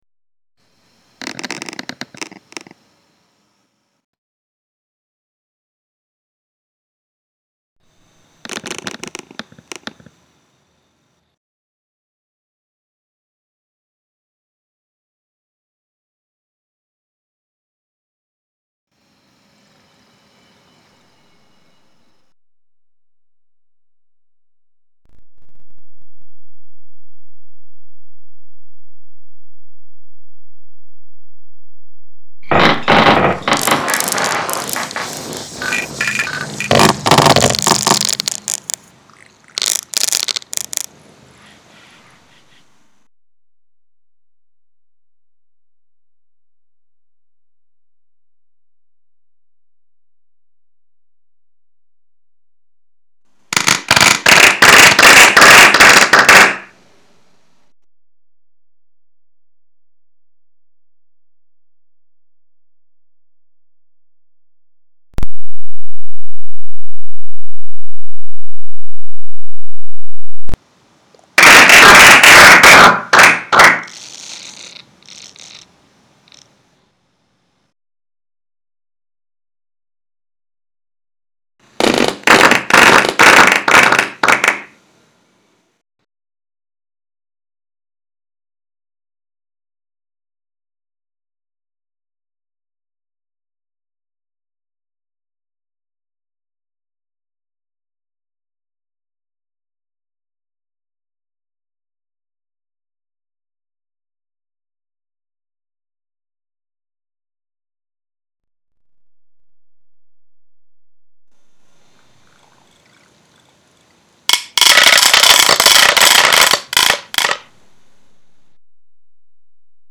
Applaus-audio.mp3